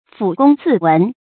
撫躬自問 注音： ㄈㄨˇ ㄍㄨㄙ ㄗㄧˋ ㄨㄣˋ 讀音讀法： 意思解釋： 反躬自問，指自我反省 出處典故： 清 昭槤《嘯亭雜錄 朱白泉獄中上百朱二公書》：「今以愚昧，于此獲罪，所知為之流涕，路人為之嘆息。